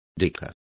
Complete with pronunciation of the translation of dickers.